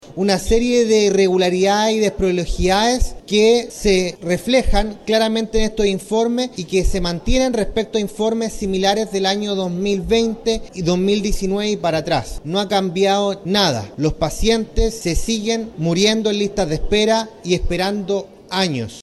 Ante ello, el presidente de esta Comisión Especial Investigadora, Tomás Lagomarsino (IND-PR) dijo que esta auditoría mostró situaciones dramáticas, incluso delictuales.